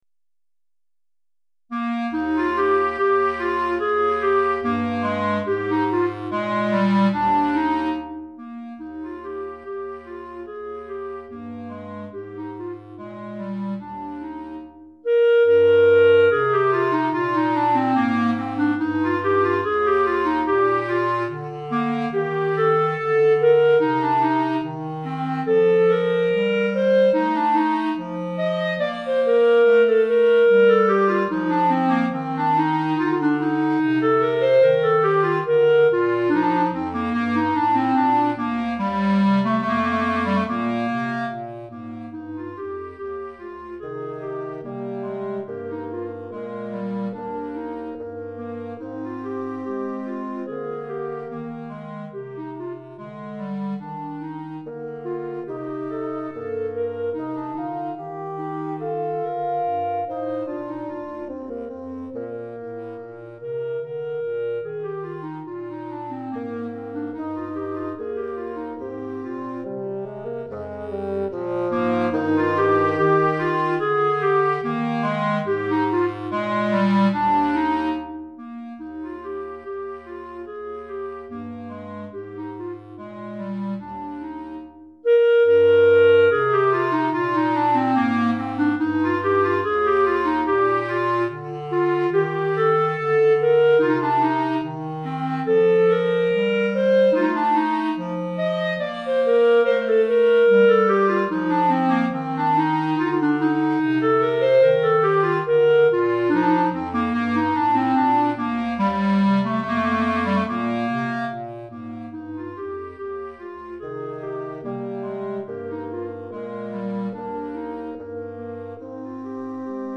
Basson et 4 Clarinettes